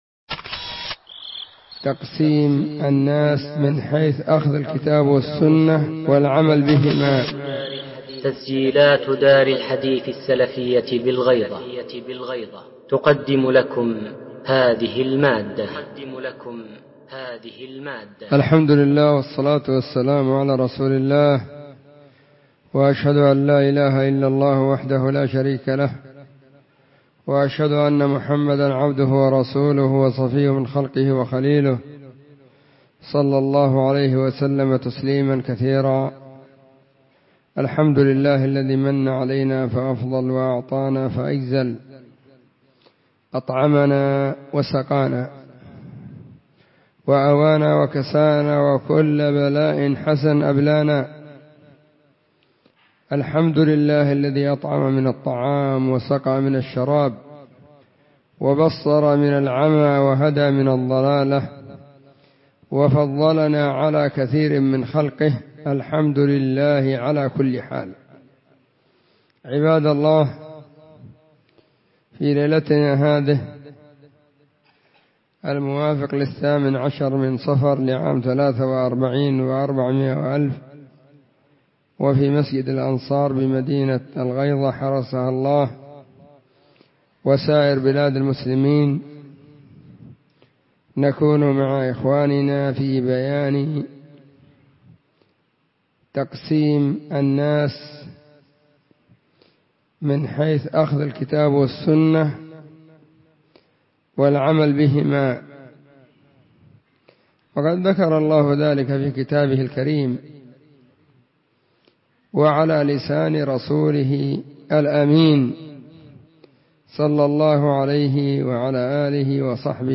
محاضرة بعنوان *(( تقسيم الناس من حيث أخذ الكتاب والسنة والعمل بهما ))*
📢 مسجد الصحابة – بالغيضة – المهرة، اليمن حرسها الله،